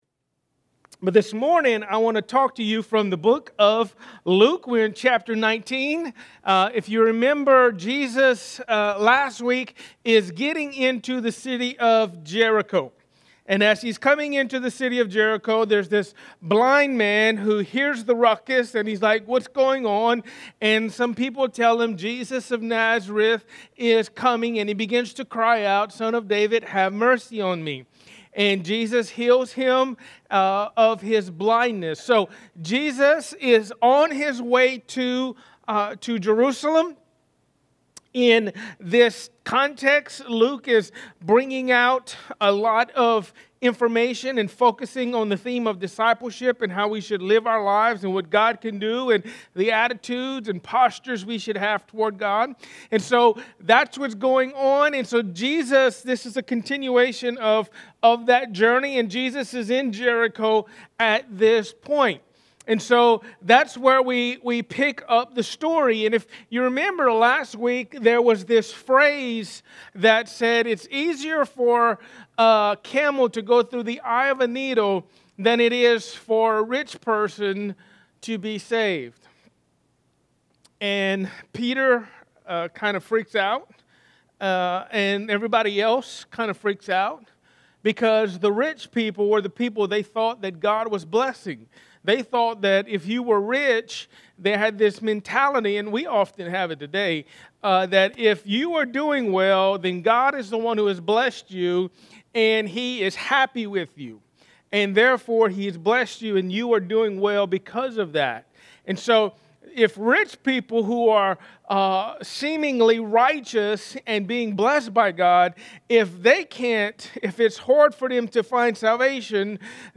Emmanuel Baptist Church
A message from the series "Luke."